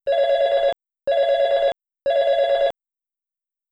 La llamada de portero es 3 rings cortos y un silencio,
ring portero.wav